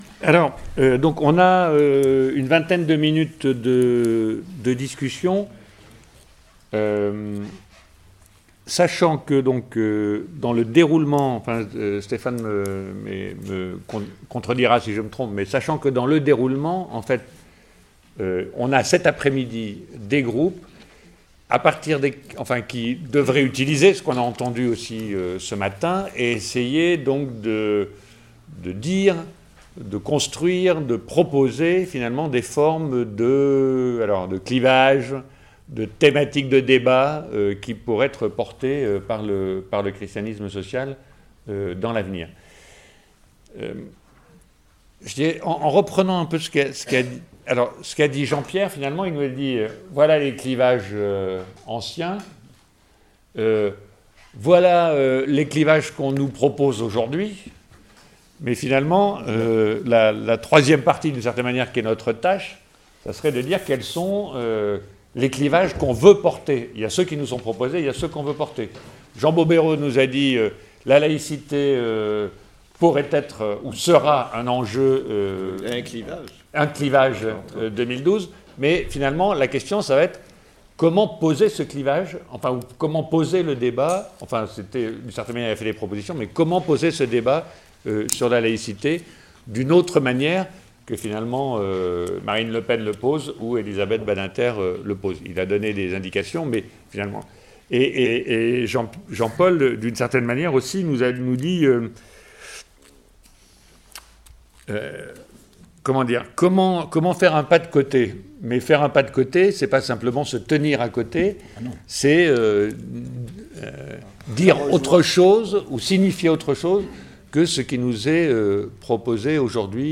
Le 1er octobre 2011 a eu lieu une nouvelle rencontre du Christianisme social à Paris.
Un débat a suivi ces exposés.
debat-clivages.mp3